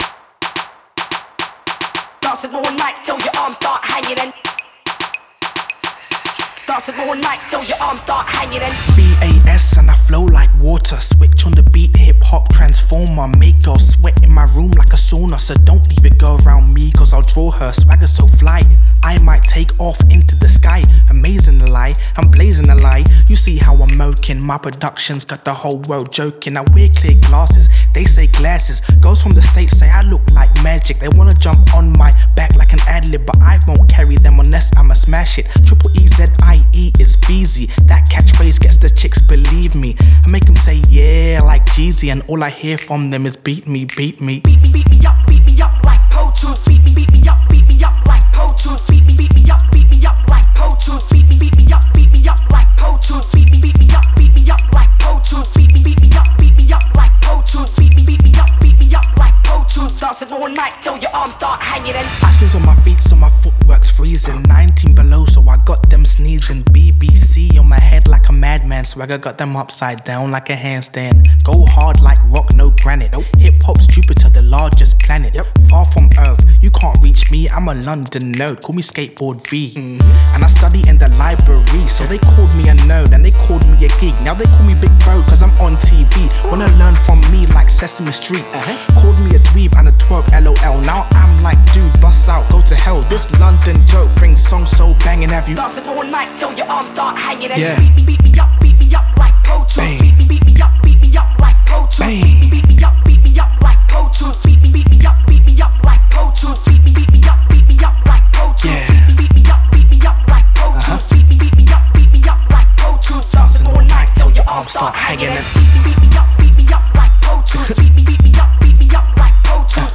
Underground Hip Hop